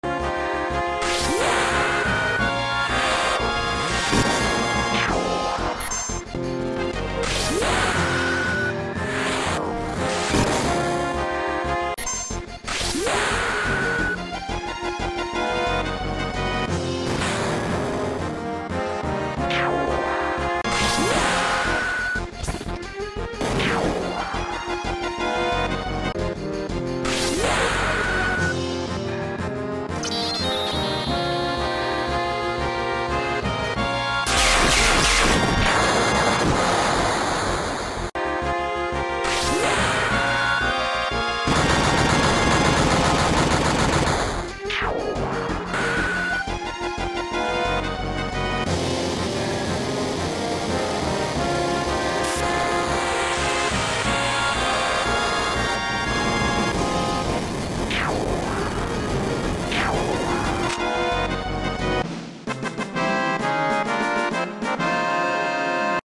Final Fantasy 7 Barrett sound effects free download